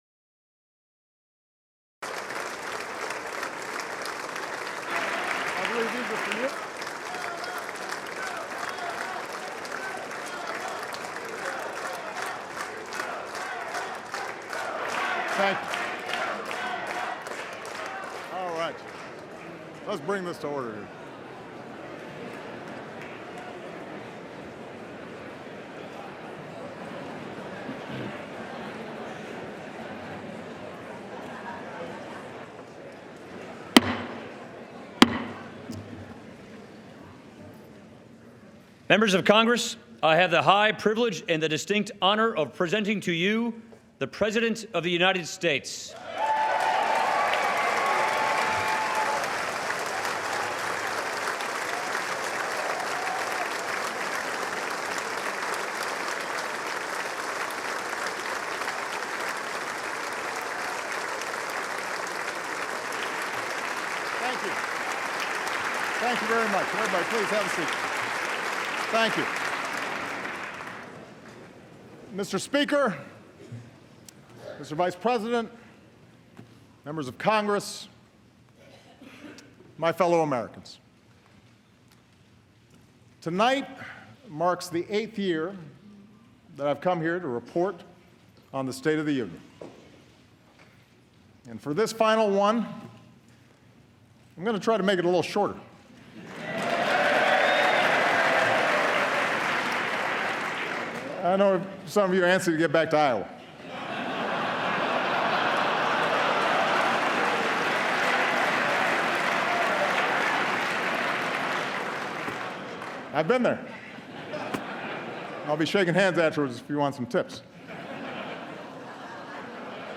U.S. President Barack Obama delivers his final State of the Union address